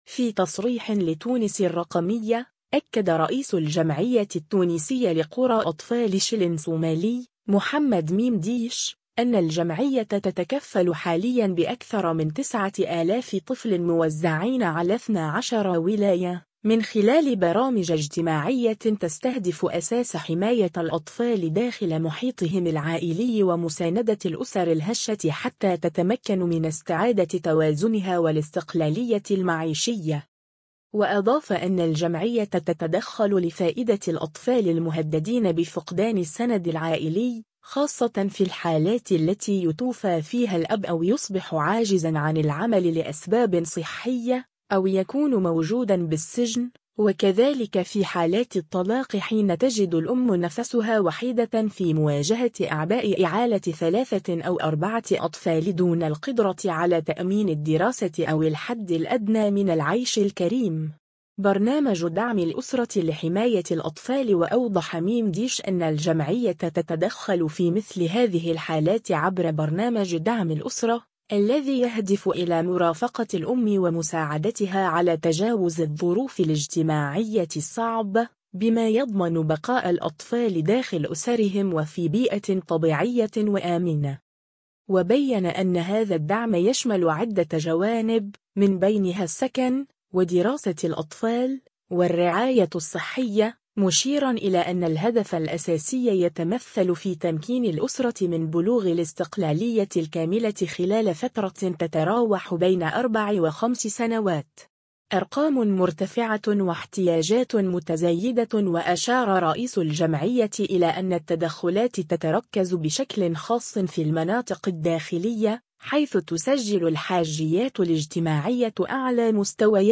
في تصريح لتونس الرقمية